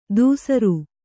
♪ dūsaru